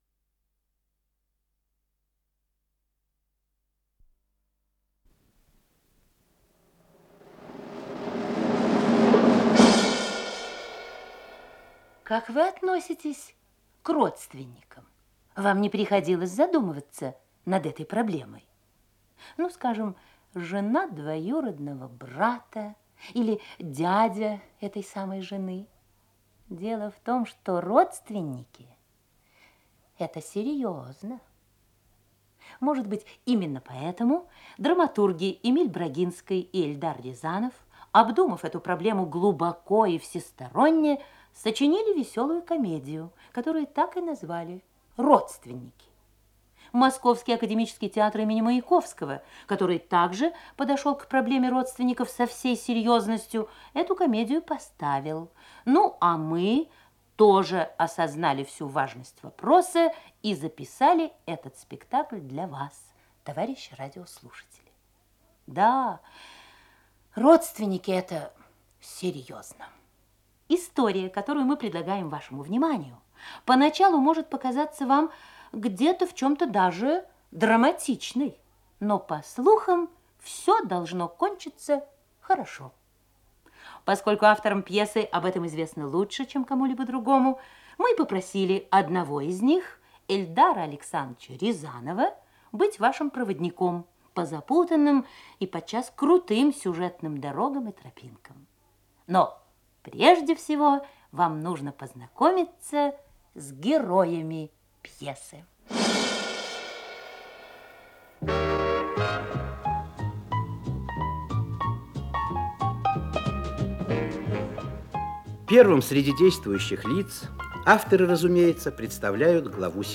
Исполнитель: Артисты Московского Академического театра им. В. Маяковского
Спектакль